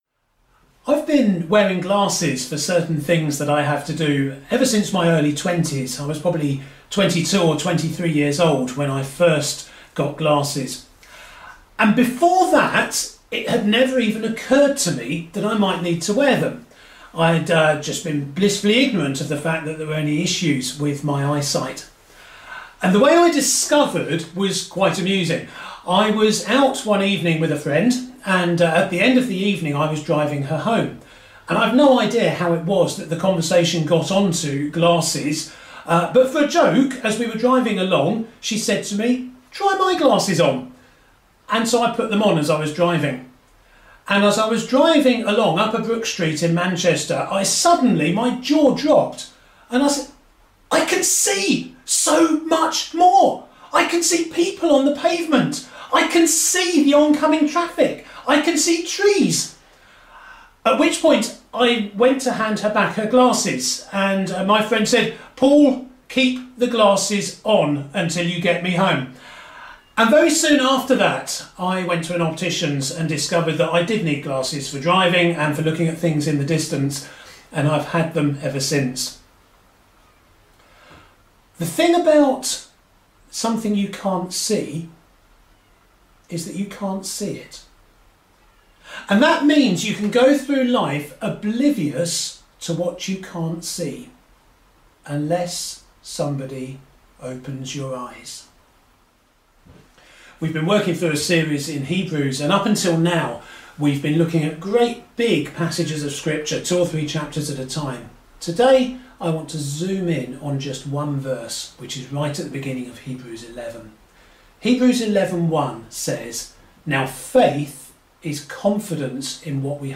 8 June 21st sermon.mp3